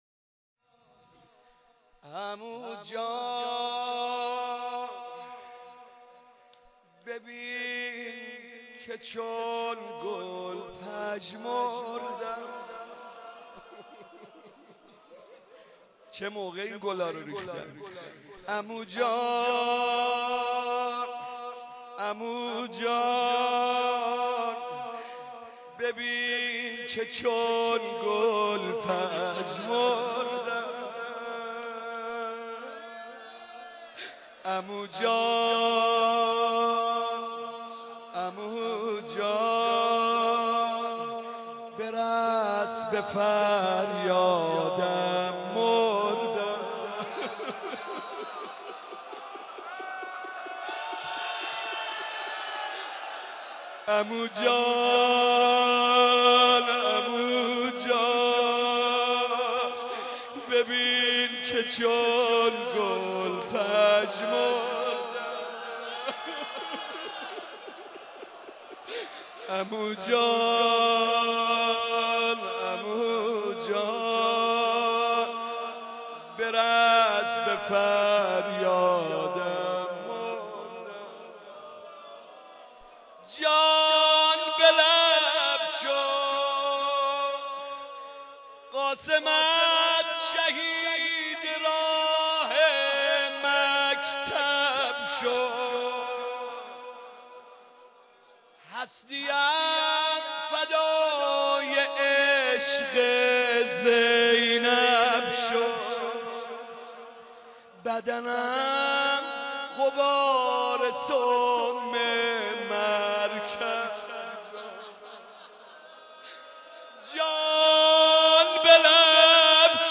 نوحه زیبای ببین که چون گل پژمردم با صدای حاج محمد رضا طاهری در شب ششم محرم سال 89